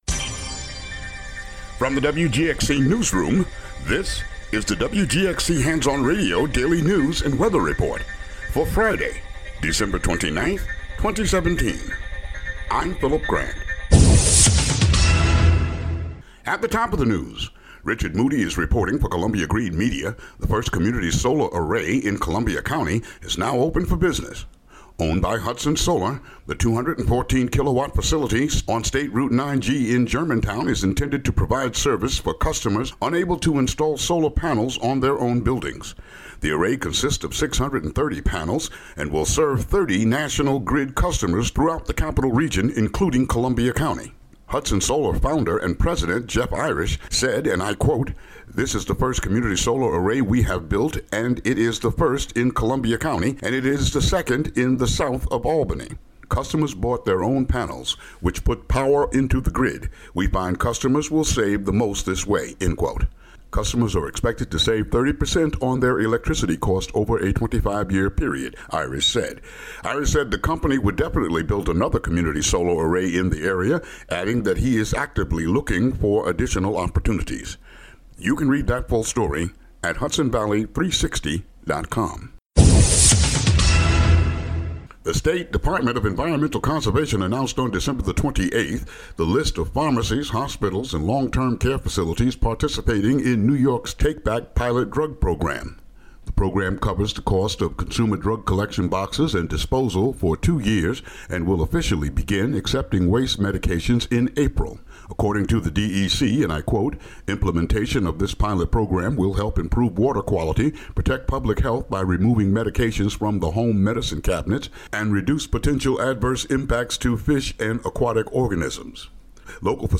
Daily local news for Fri., Dec. 29.